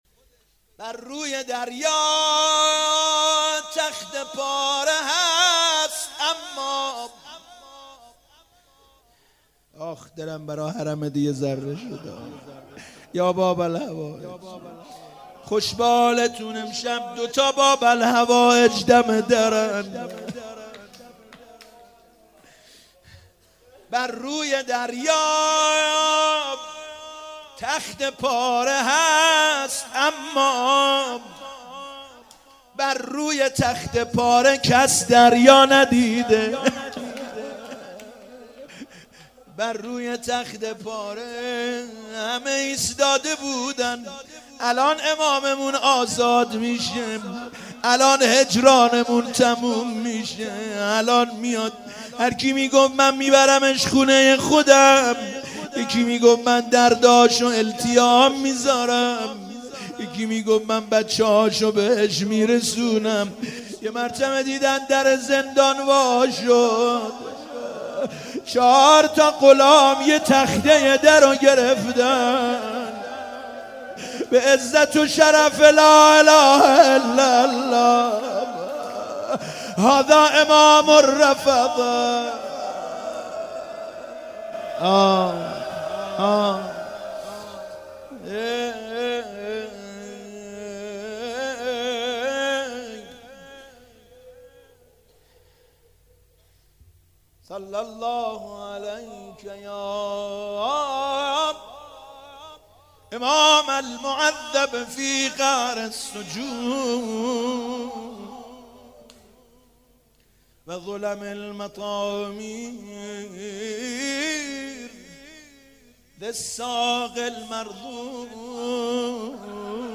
شهادت امام کاظم (ع)97 بیت العباس (ع) روضه بر روی دریا